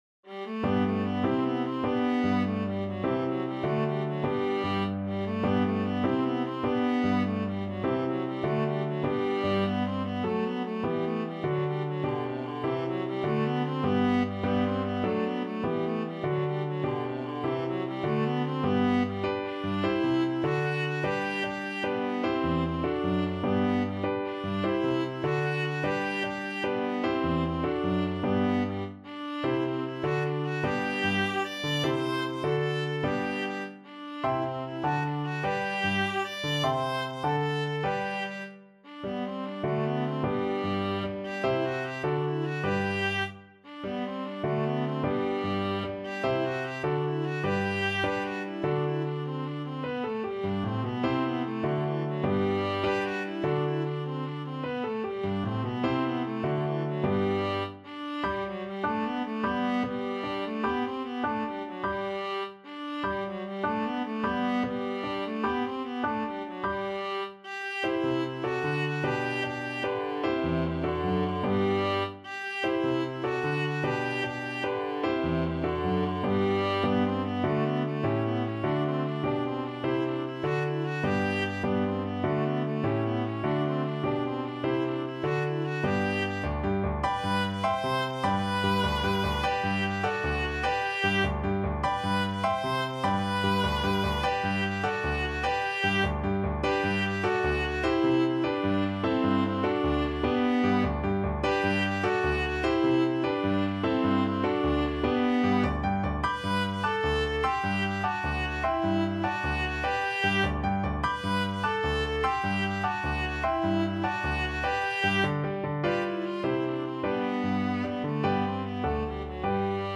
Viola
G major (Sounding Pitch) (View more G major Music for Viola )
6/8 (View more 6/8 Music)
Classical (View more Classical Viola Music)